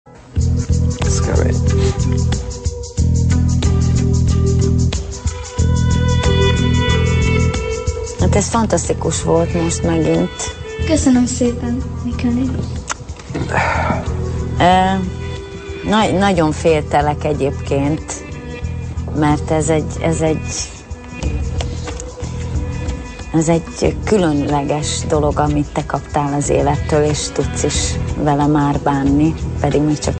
Please ID this! film score/fim music from an action movie
Sorry for the talking ppl, couldn't be helped.